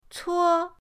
cuo1.mp3